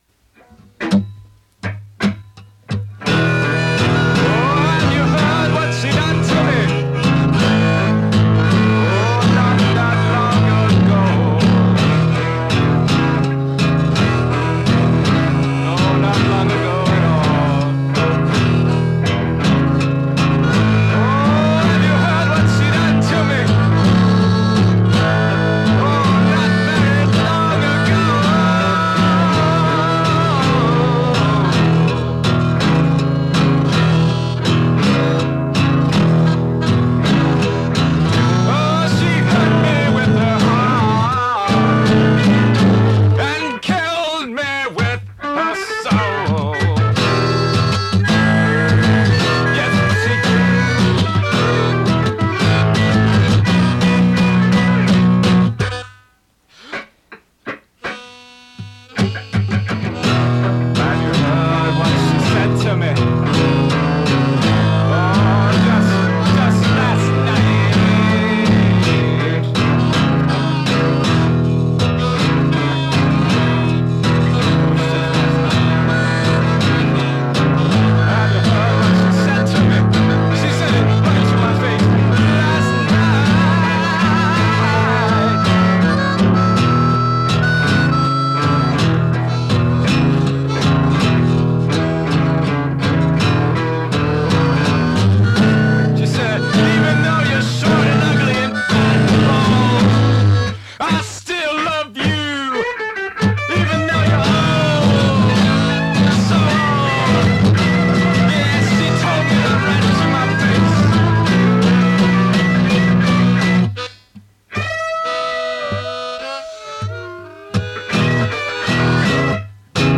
A wailing blues number from yours truly.